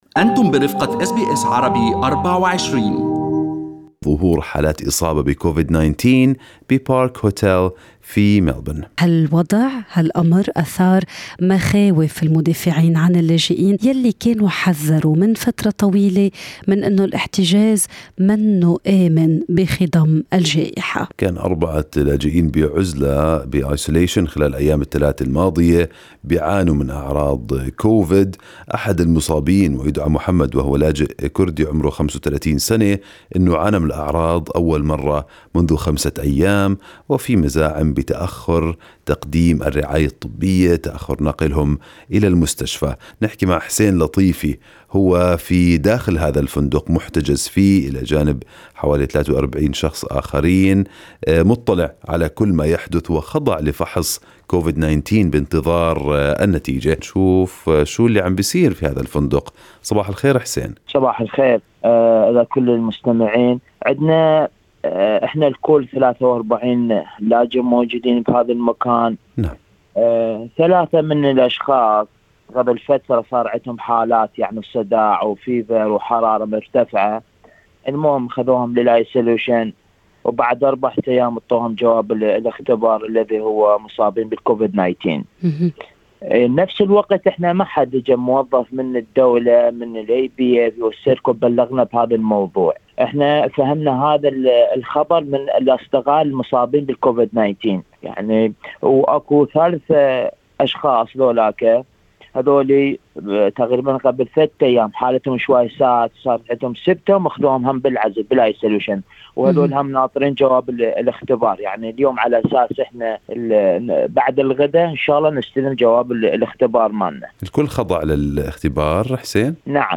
"الوضع مأساوي": طالب لجوء يصف وضع المحتجزين في Park Hotel في ملبورن بعد تسجيل إصابات كوفيد-19